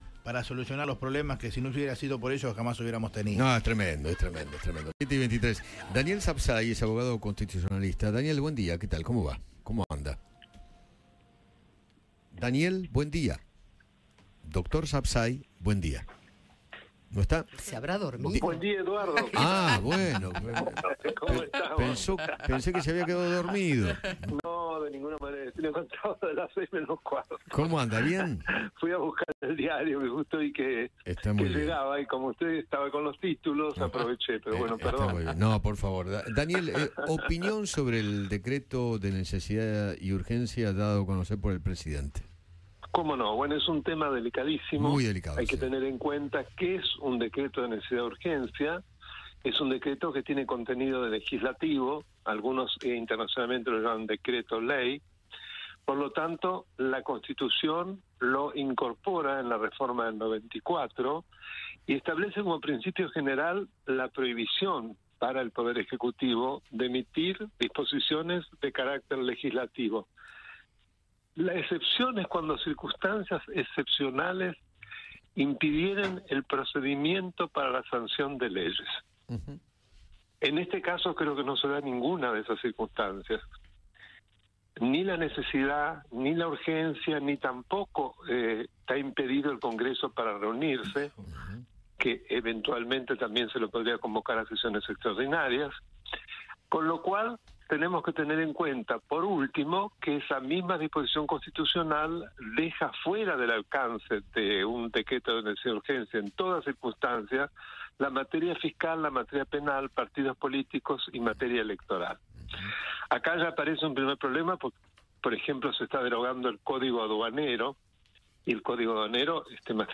El abogado constitucionalista, Daniel Sabsay, habló con Eduardo Feinmann sobre el DNU que anunció ayer el presidente Javier Milei y consideró que “más allá del contenido, que estoy de acuerdo con la gran mayoría de las medidas que se proponen, no se pueden hacer las cosas de cualquier manera”.